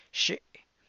xi[xiìi’]